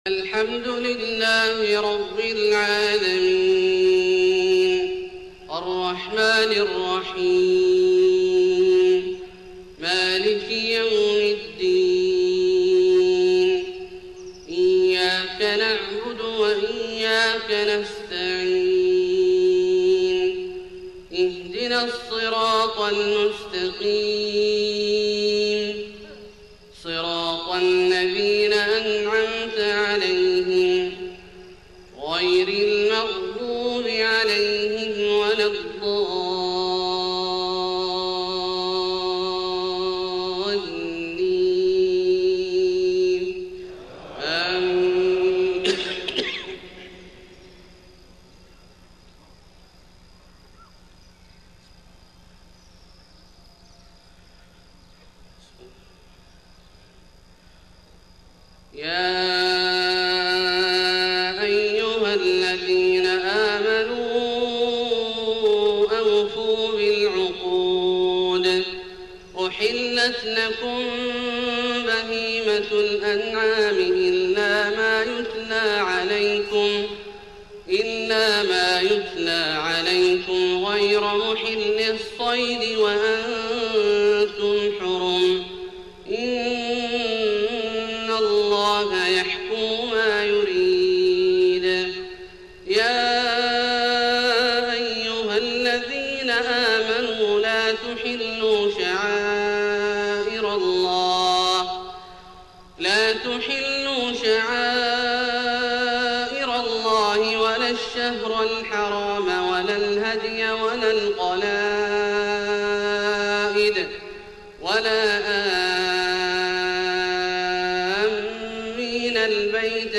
صلاة الفجر 11 ربيع الأول 1431هـ فواتح سورة المائدة {1-7} > 1431 🕋 > الفروض - تلاوات الحرمين